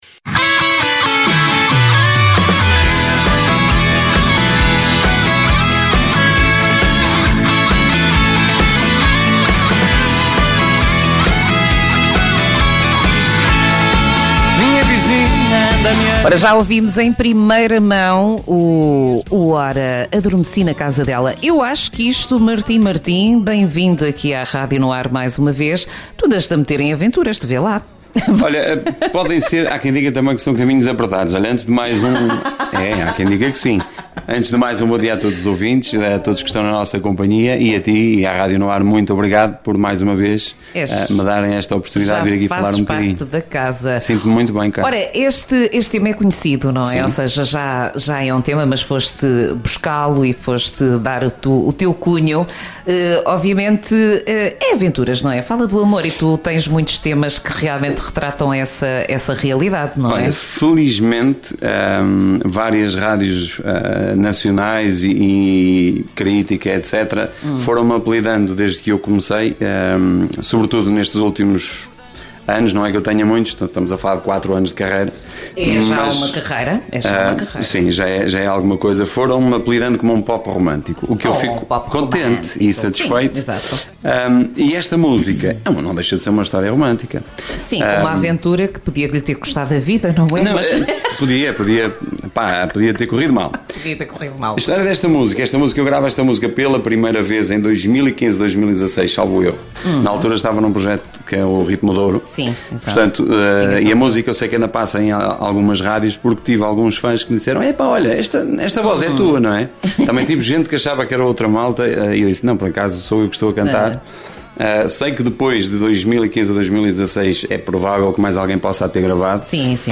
Entrevista
em direto nas Manhãs NoAr